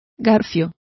Complete with pronunciation of the translation of crampons.